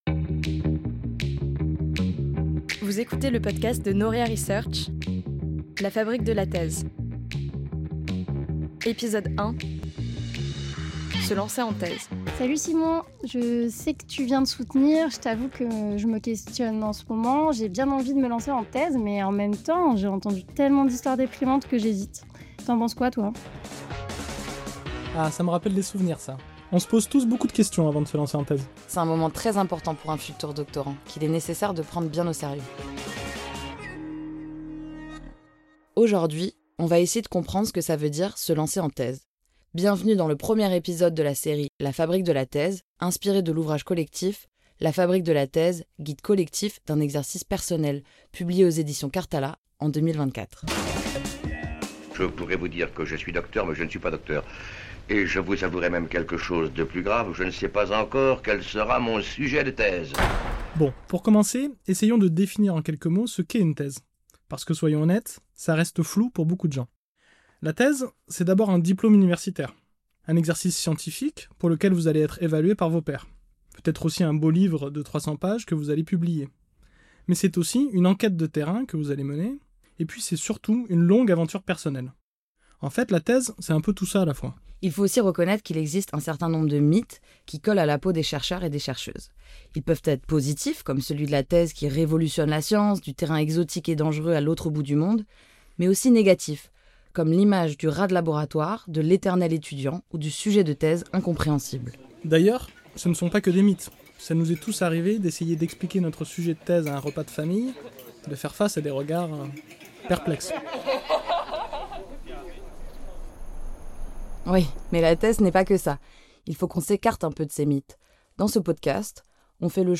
Entretien
C’est par un simple message vocal que tout commence.
A travers un dialogue mêlé à des voix de jeunes chercheur-ses, ce premier épisode de La fabrique de la thèse propose des conseils concrets pour réfléchir à son sujet, choisir sa discipline… et comprendre ce que signifie réellement se lancer en thèse.